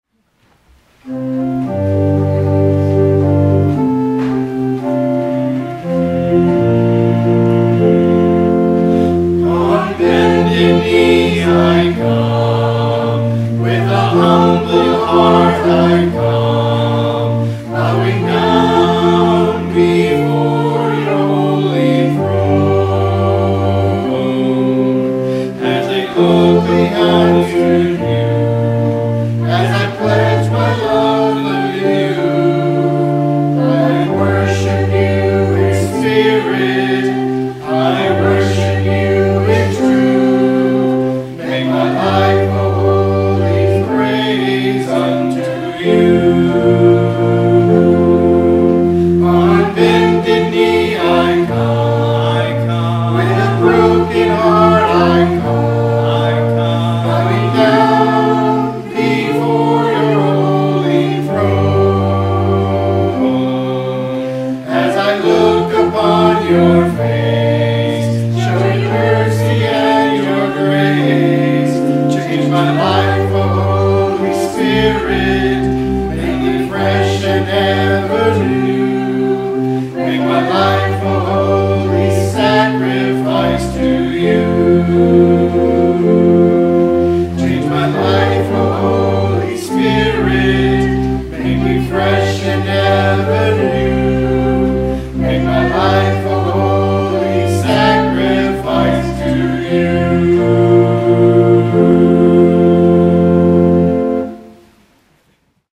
Anthem